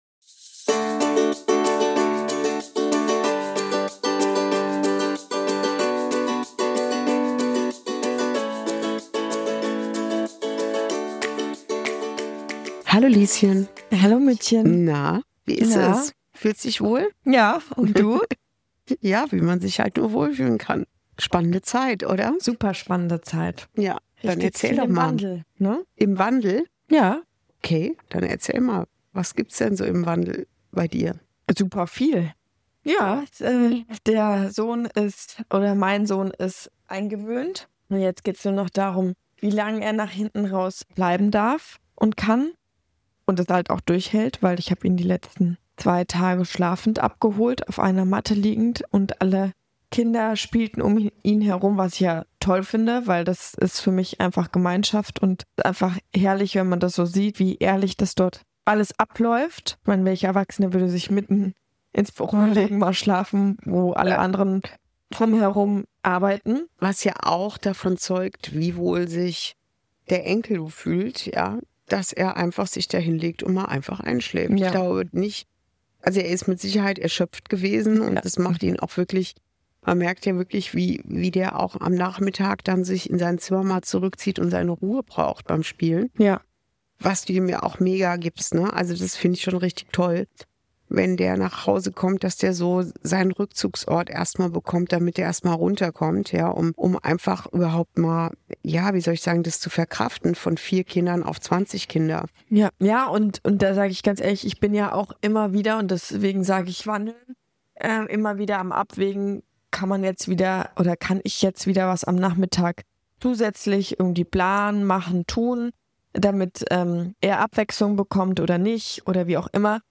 Folge 19: Zwischen Kontrolle und Vertrauen – wenn das Leben umstellt ~ Inside Out - Ein Gespräch zwischen Mutter und Tochter Podcast